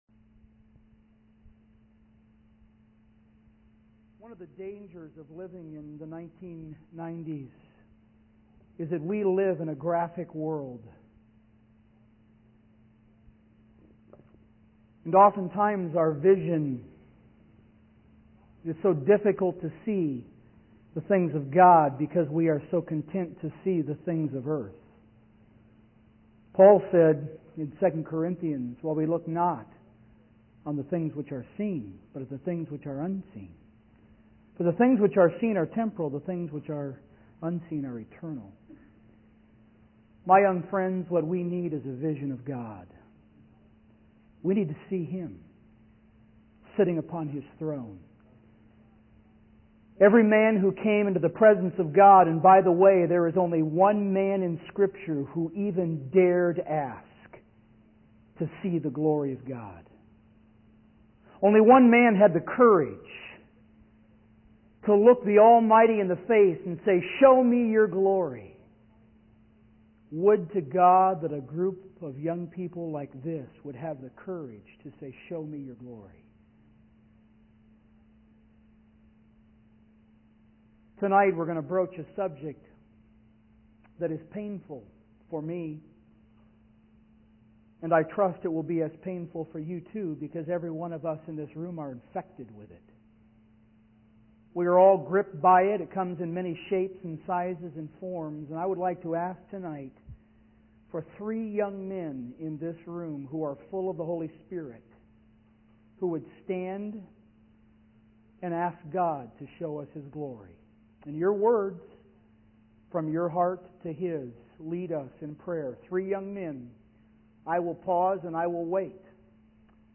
In this sermon, the preacher highlights the danger of living in a graphic world in the 1990s, where our focus on earthly things hinders our ability to see the things of God. He emphasizes the need for a vision of God and references Paul's words in 2 Corinthians about looking at the unseen rather than the seen.